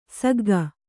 ♪ sagga